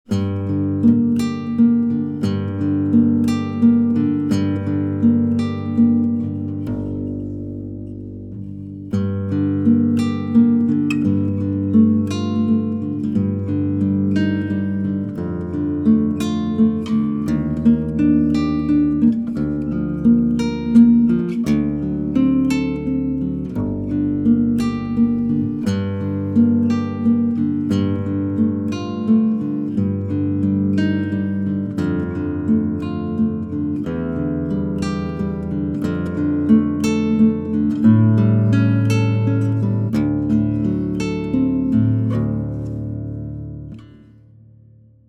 Guitare luth d’inspiration baroque
Commande spéciale, 12 cordes nylon, du grave à l’aigu : FGABCD EADGBE. Action réglable, manche, fond et éclisses en érable ondé, table épicéa, touche palissandre, finition huiles dures.
guitareluthbaroque2024_audio.mp3